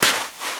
High Quality Footsteps / Sand
STEPS Sand, Walk 05.wav